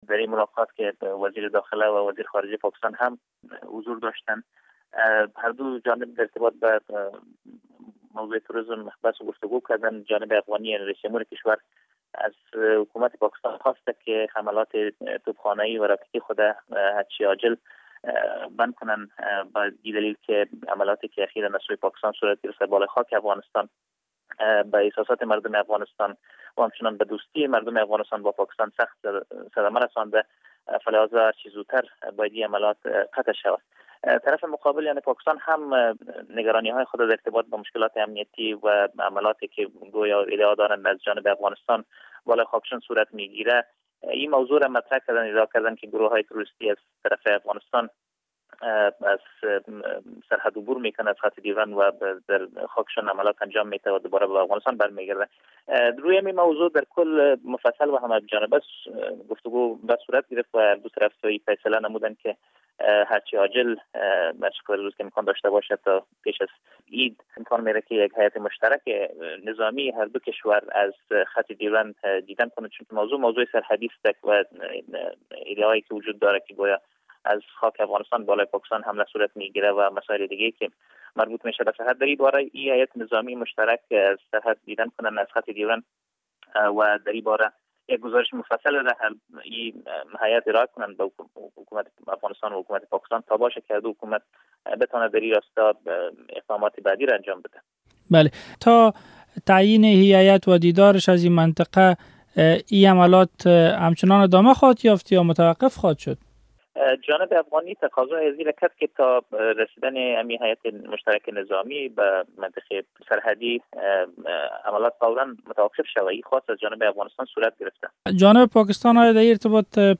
مصاحبه در مورد اشتراک حامد کرزی در کنفرانس سازمان کشورهای اسلامی